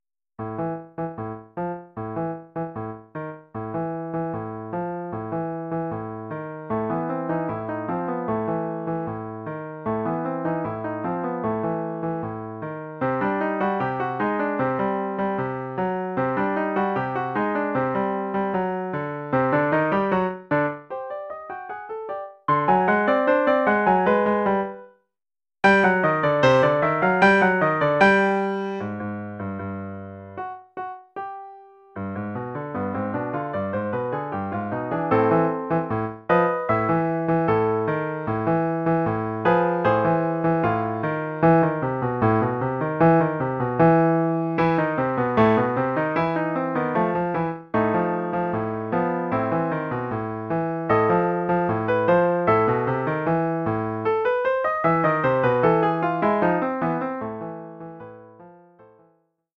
Oeuvre pour piano solo.